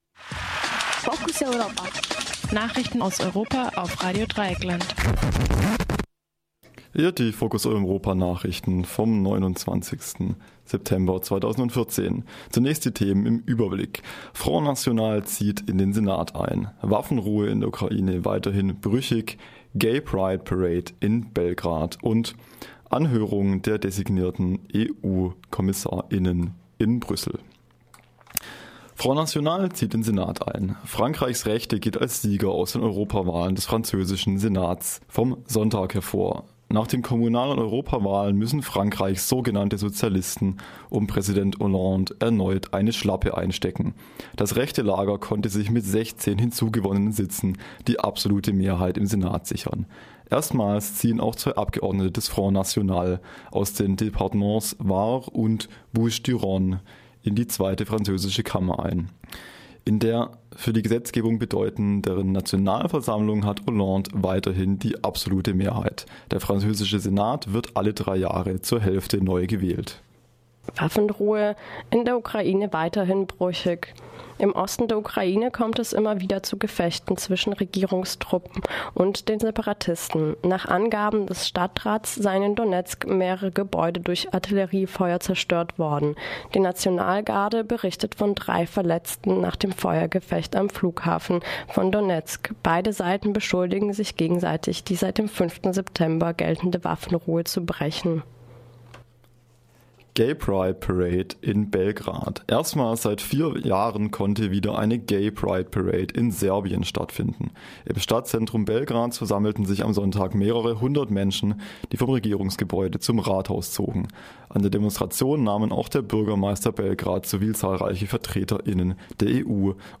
Focus Europa Nachrichten vom Montag den 29. September